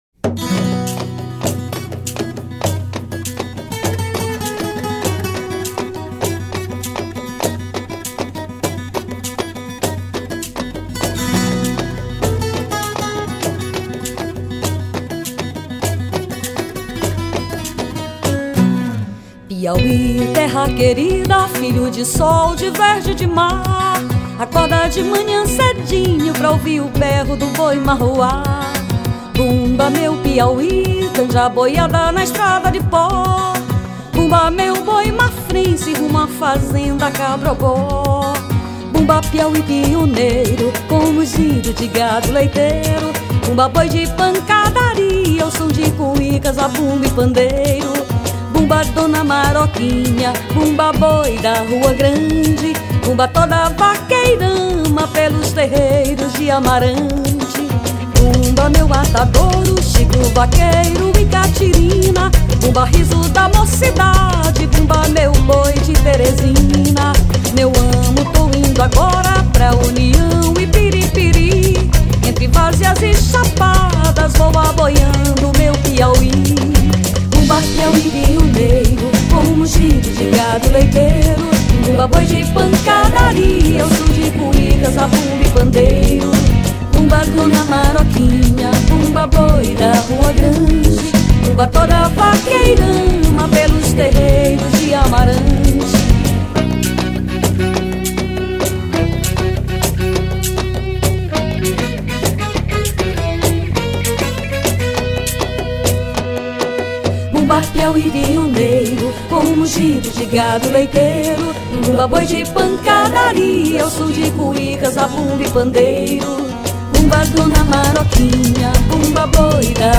2219   03:44:00   Faixa:     Baião
Baixo Acústico, Teclados
Vocal
Bateria, Percussão
Guitarra, Violao Acústico 6
Trombone de Vara
Flauta, Sax Alto